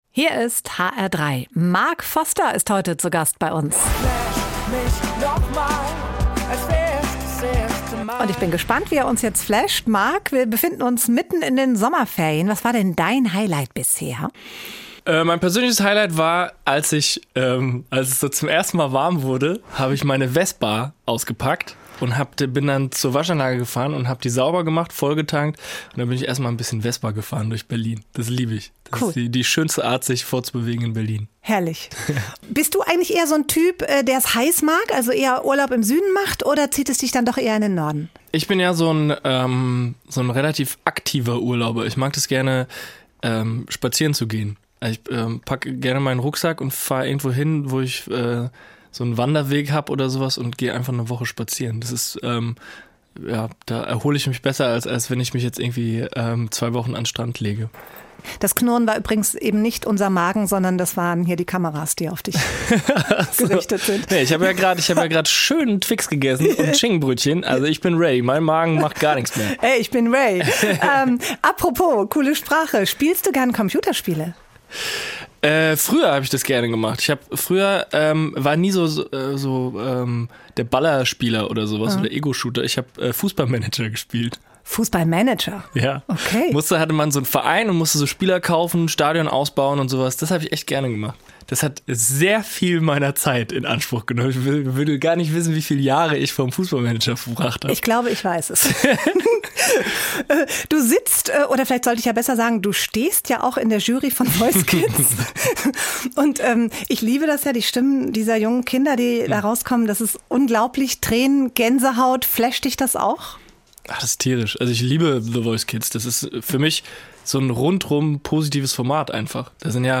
Interview mit Mark Forster